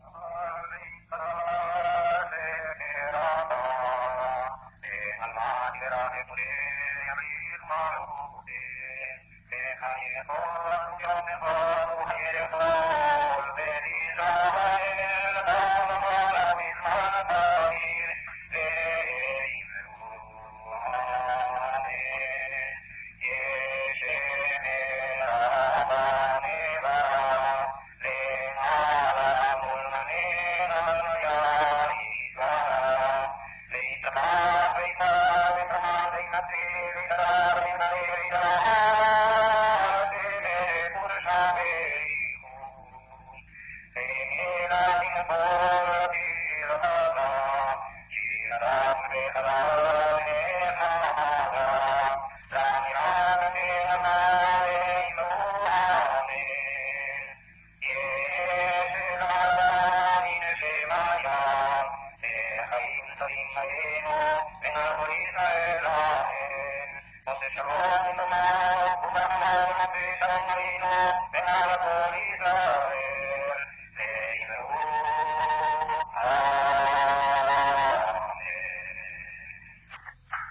Altre registrazioni storiche
Gerusalemme, Tempio Italiano, anni 50